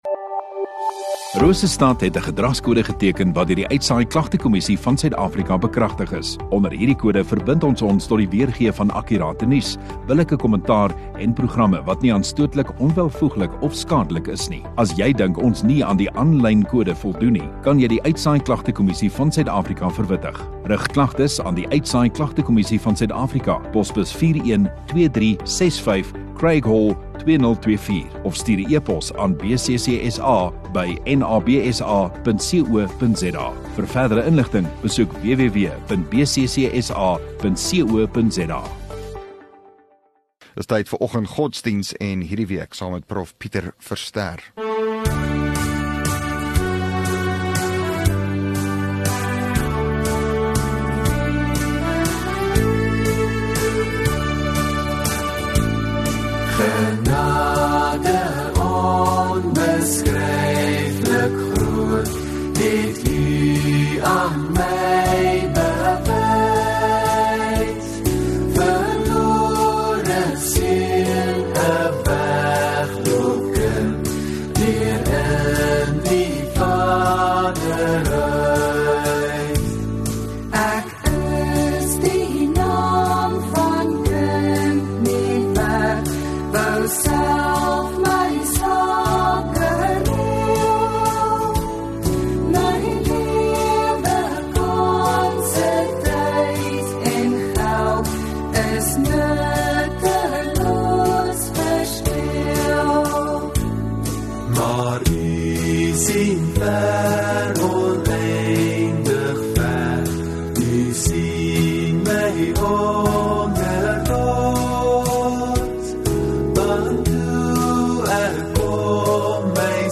29 Apr Maandag Oggenddiens